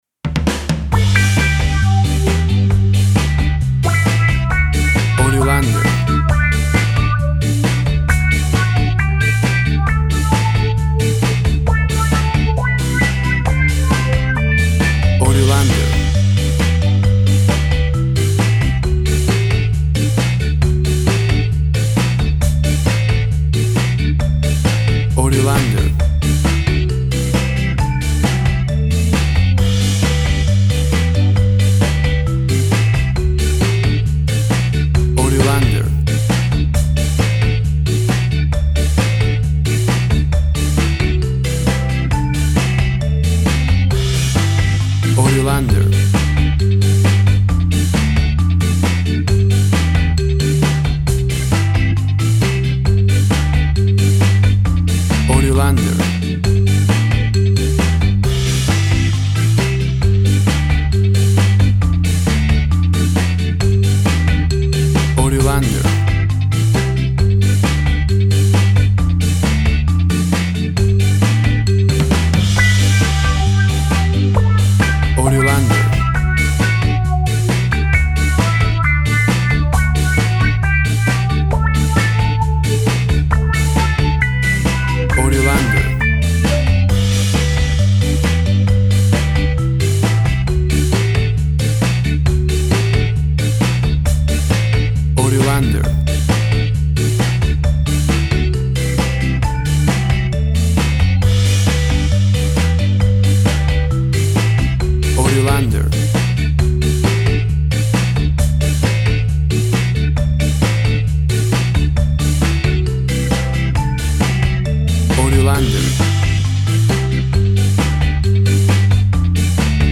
WAV Sample Rate 16-Bit Stereo, 44.1 kHz
Tempo (BPM) 135